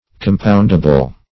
Compoundable \Com*pound"a*ble\, a. That may be compounded.